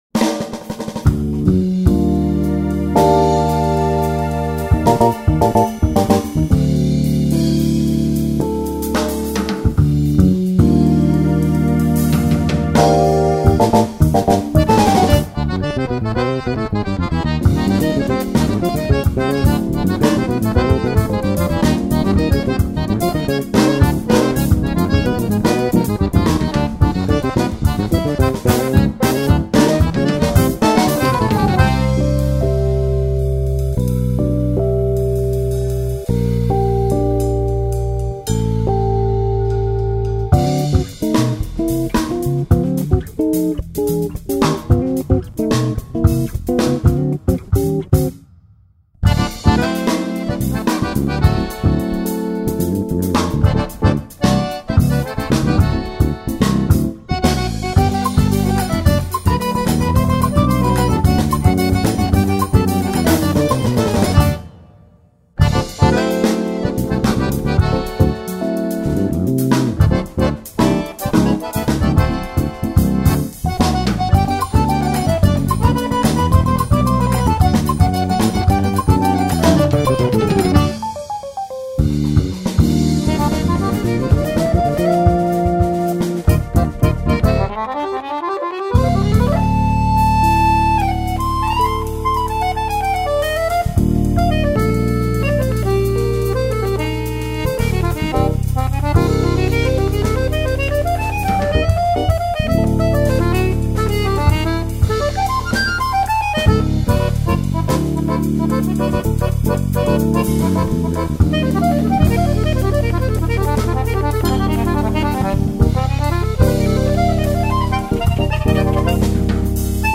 2540   03:57:00   Faixa: 2    Jazz